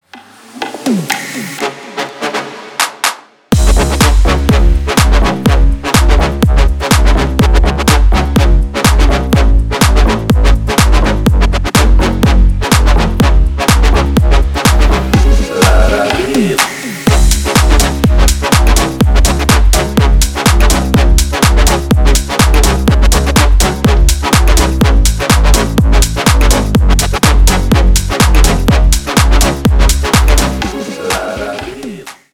Ремикс # без слов
клубные